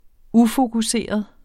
Udtale [ ˈufoguˌseˀʌð ]